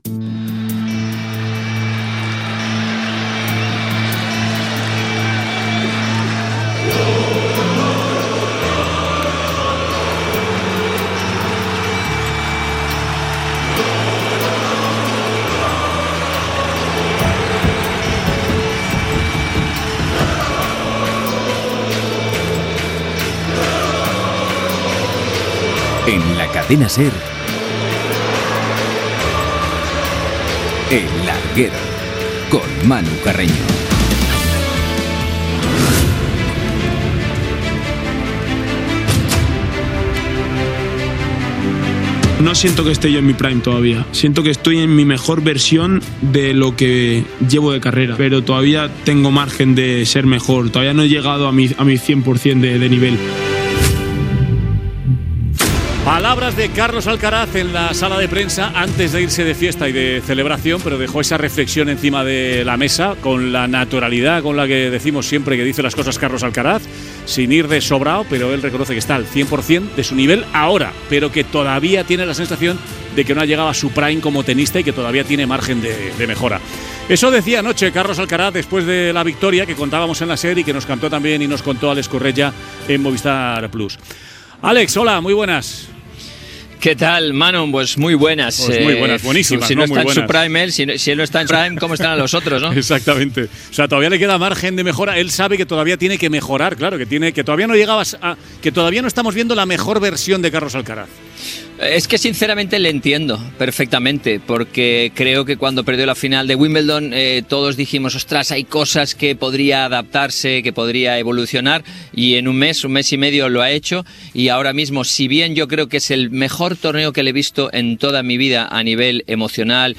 Careta del programa, presentació i fragment d'una entrevista al ex-tennista Álex Corretja i al tennista Carlos Alcaraz.
Esportiu